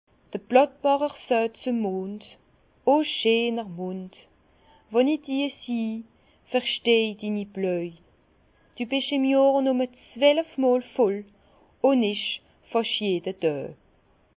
Bas Rhin
Ville Prononciation 67
Herrlisheim